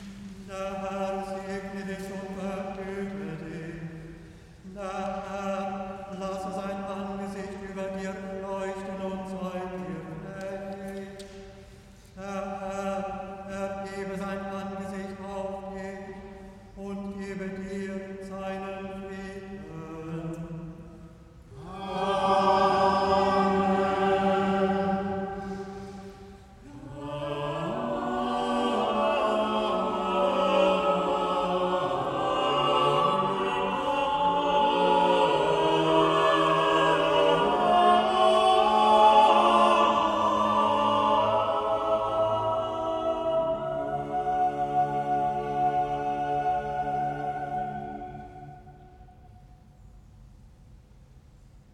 Römische und venezianische Chormusik treffen in diesem NoonSong aufeinander: beschwingt, elegant, predigend und klangprächtig.